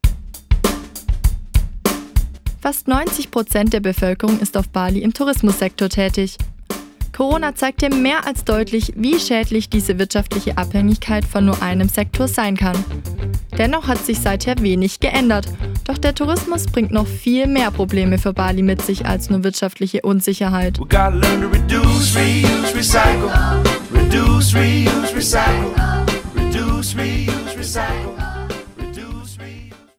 Teaser_486.mp3